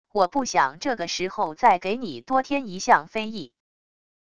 我不想这个时候再给你多添一项非议wav音频生成系统WAV Audio Player